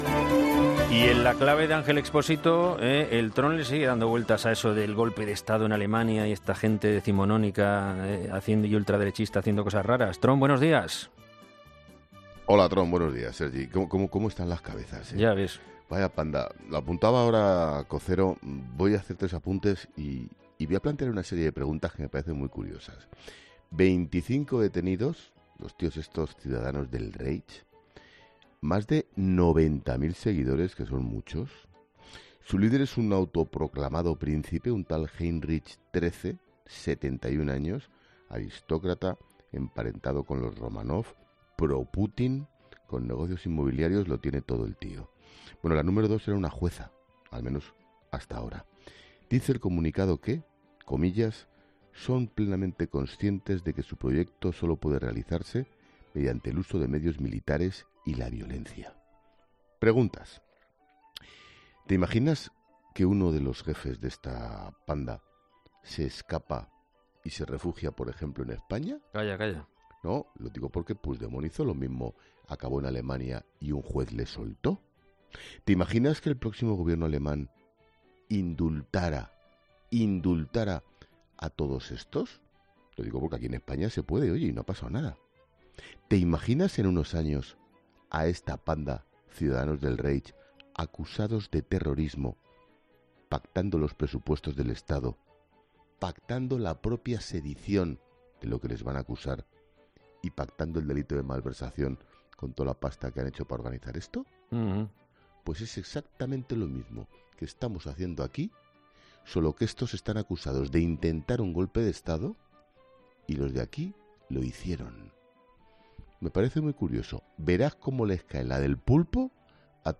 Ángel Expósito ha reflexionado esta mañana en 'El paseíllo del tron' de Herrera en COPE sobre la situación vivida estas últimas horas en Alemania donde más de una veintena de personas han sido detenidas acusadas de intentar dar un golpe de Estado en aquel país.